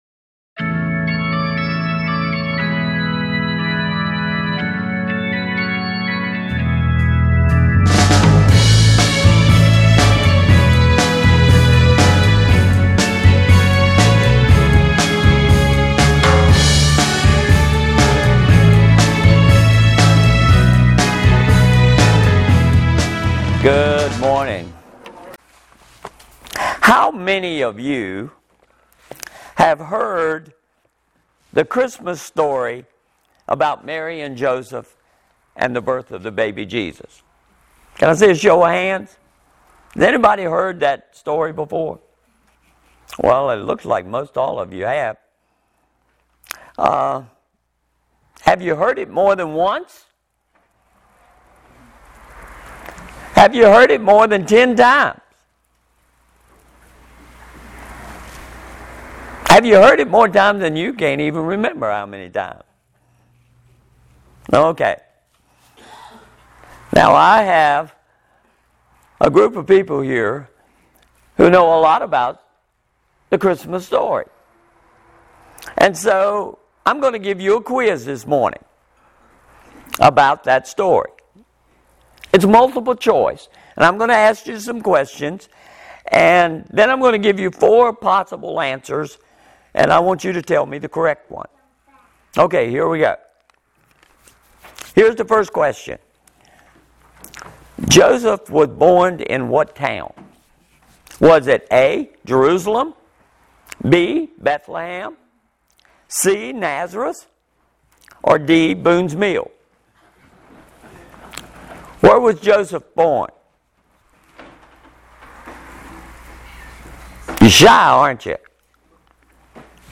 -------------------------- Sermon Synopsis -----------------------------